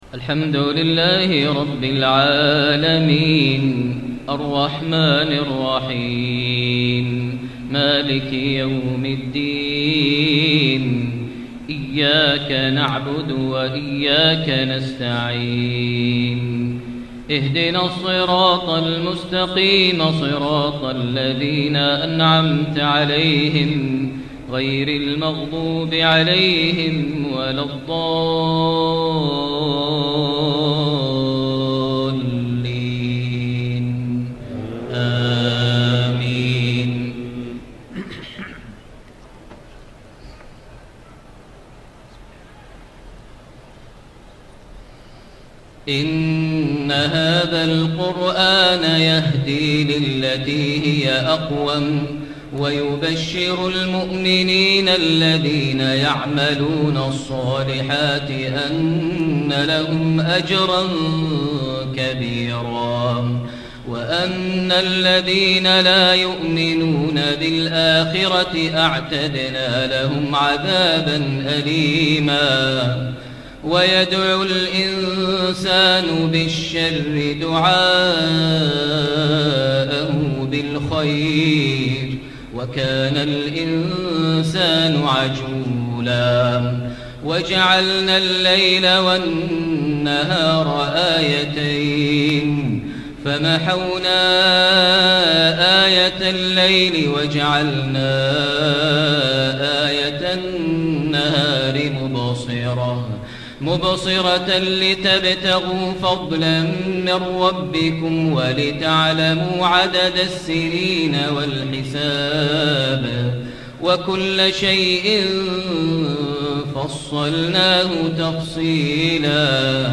Isha Prayer Surah AlIsra’ > 1436 H > Prayers - Maher Almuaiqly Recitations